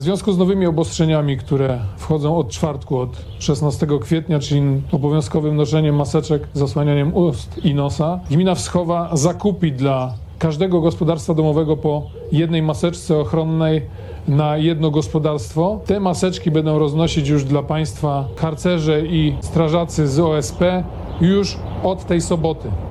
– W ten sposób, co prawda w małym stopniu, ale chcemy pomóc naszym mieszkańcom w wywiązywaniu się z kolejnych obostrzeń – powiedział burmistrz Konrad Antkowiak: